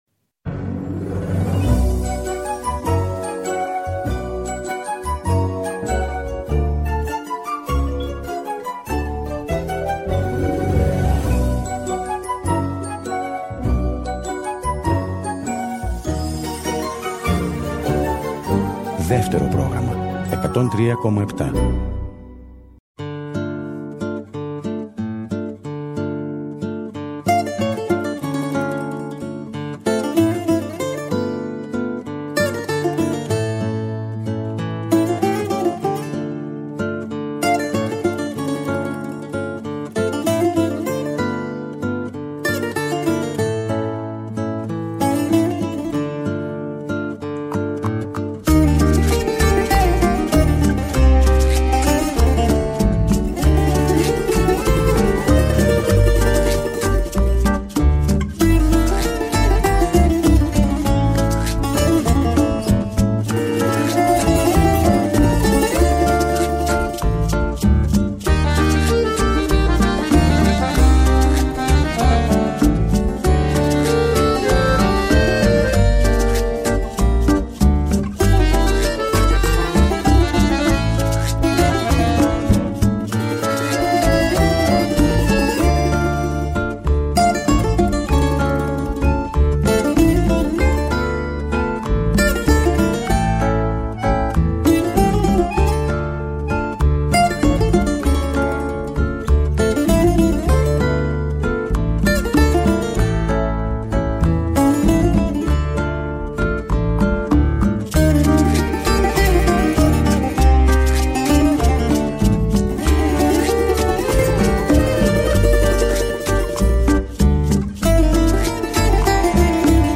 Τί καλύτερο για το Σαββατόβραδο από μια εκπομπή με τραγούδια που αγαπήσαμε;